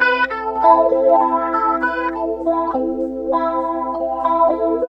70 GTR 3  -L.wav